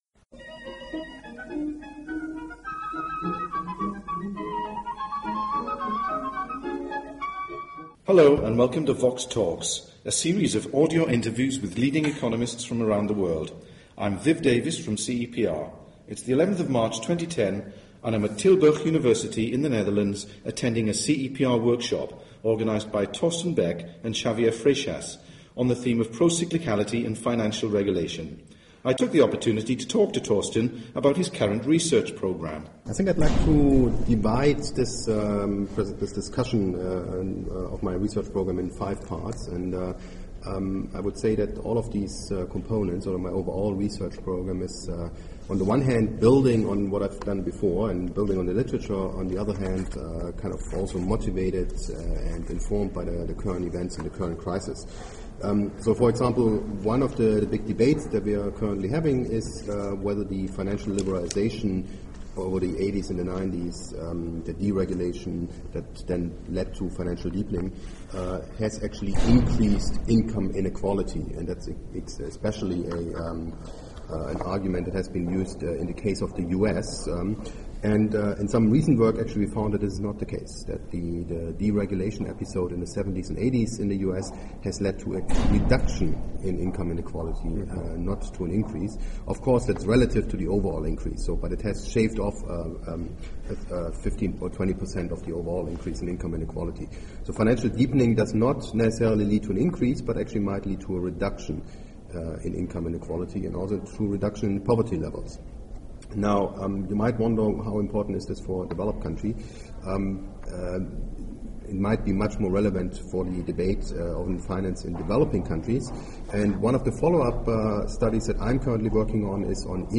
The interview was recorded at Tilburg University in April 2010.